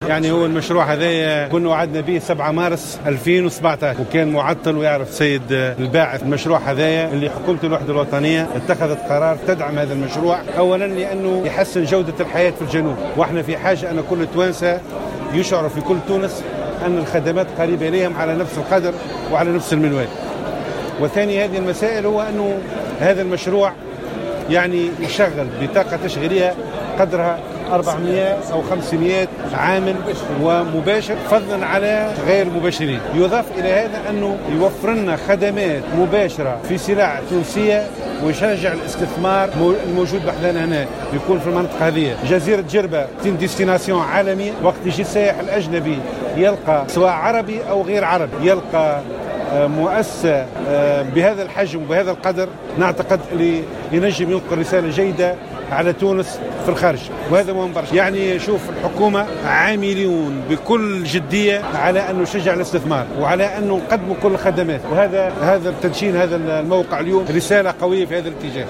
أشرف وزير أملاك الدولة والشؤون العقارية مبروك كرشيد، مساء اليوم الأربعاء 27 جوان 2018، على افتتاح مركّب "بورقو مول" بمنطقة ميدون من معتمدية جربة بولاية مدنين.